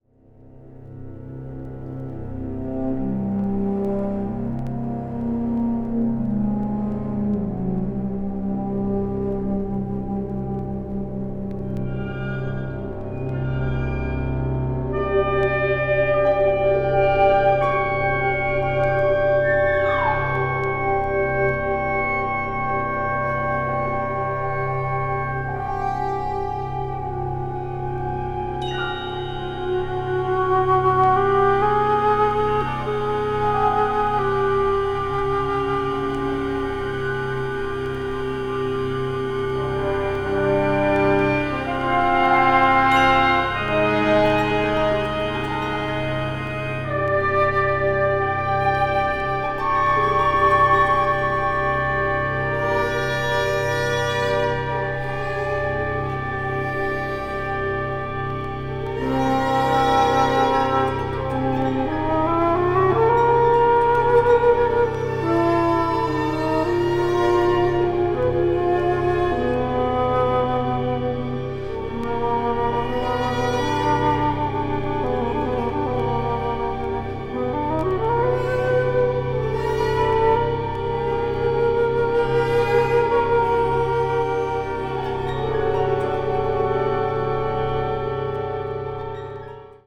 media : EX/EX(わずかにチリノイズが入る箇所あり)
シンセサイザーのような音も聞こえてきます。